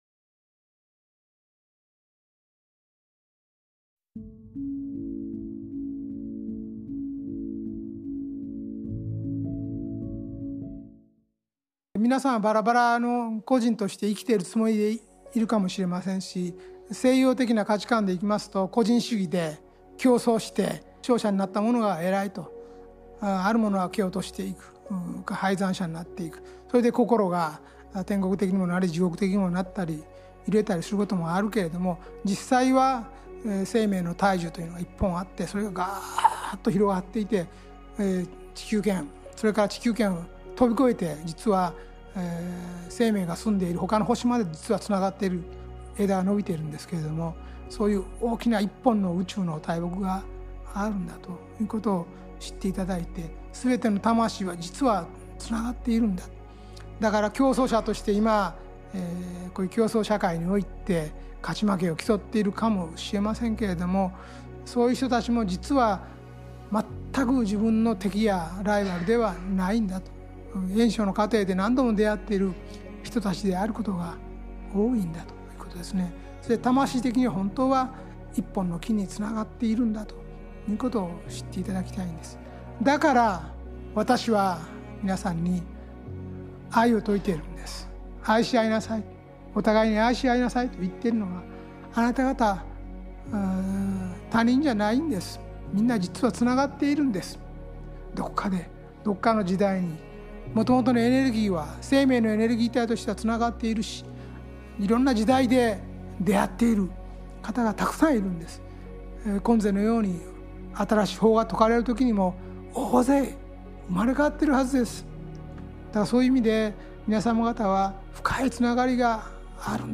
ラジオ番組「天使のモーニングコール」で過去に放送された、幸福の科学 大川隆法総裁の説法集です。